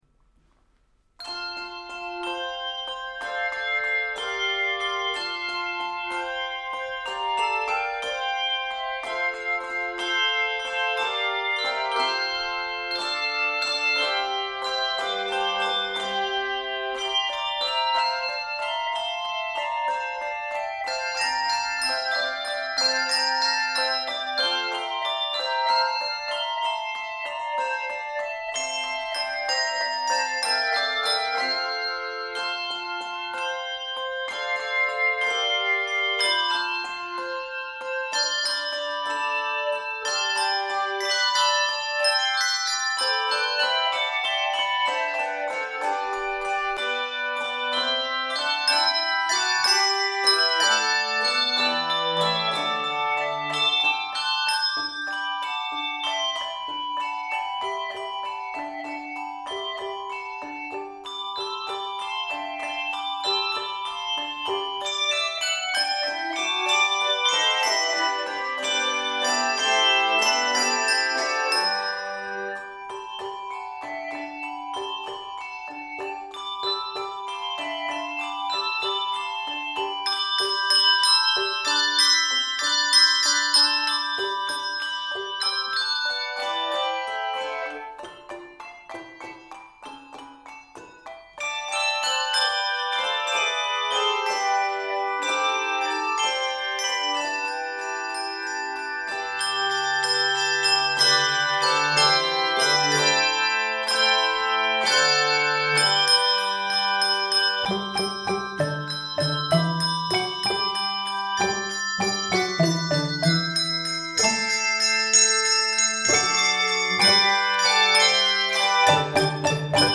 merry setting
Keys of F Major and G Major.